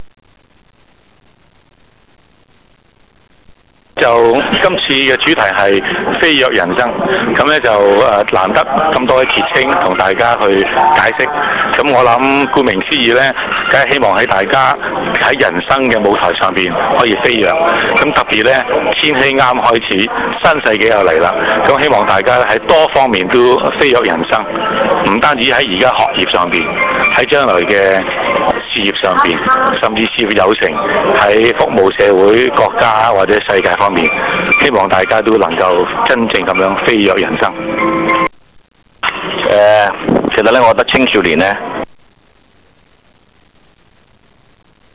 由青少年暑期活動員會及傑出青年協會合辦的「飛躍人生」講座經已於十一月二十日完滿結束。
當晚香港大球場體育大樓的賽馬會演講廳座無虛設，參加者多數是青少年。